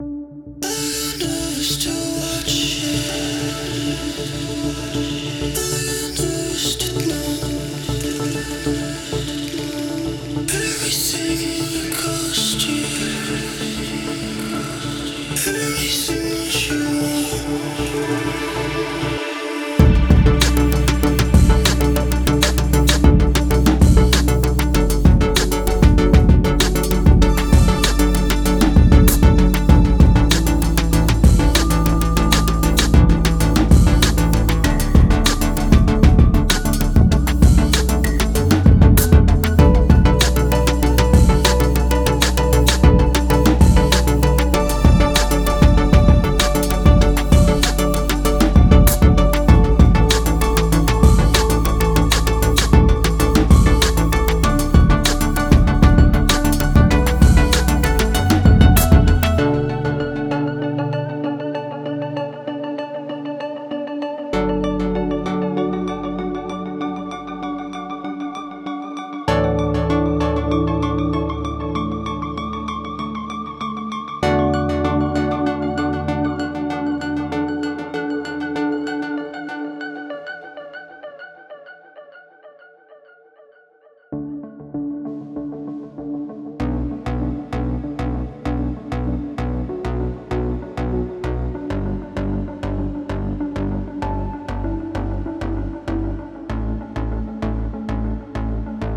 Indie Electronic